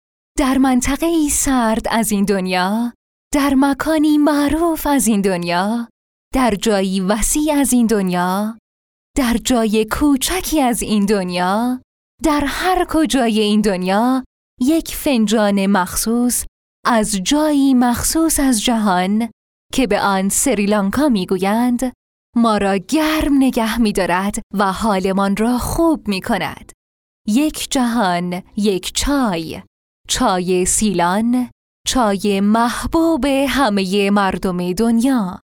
Female
Young
Adult
Commercial Tea Silan
Ai naration